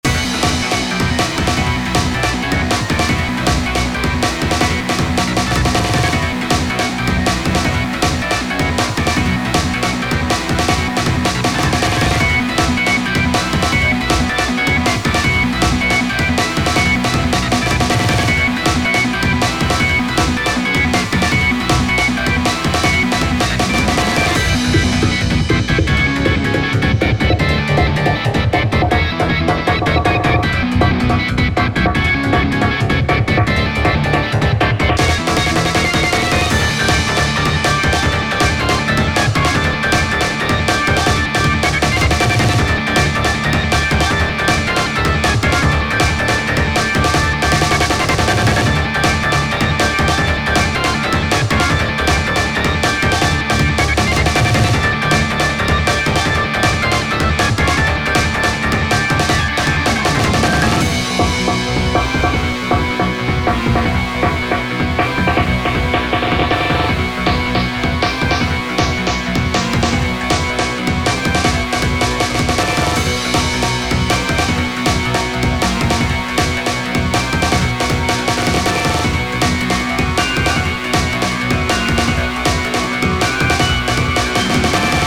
💬狡猾で狂気的なキャラクターをイメージした曲です。ハモンドオルガンをメインとしたハードで緊張感のある曲にしました。